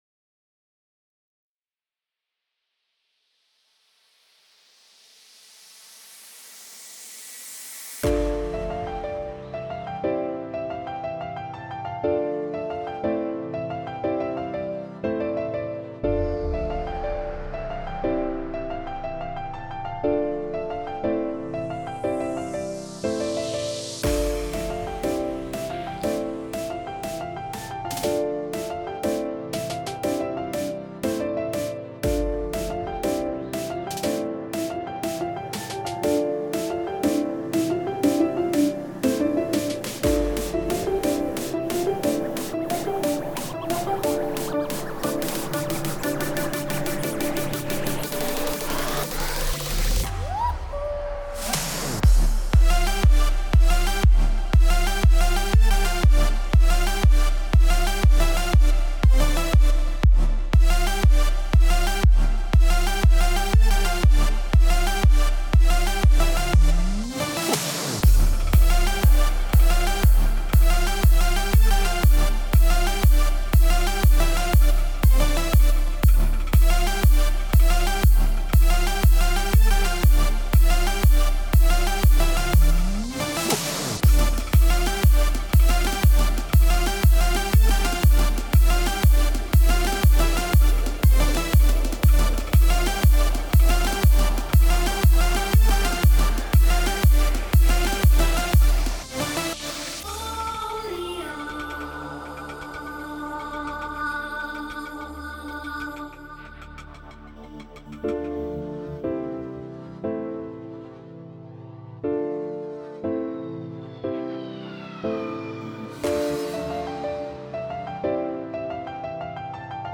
High quality Sri Lankan remix MP3 (5.9).
remix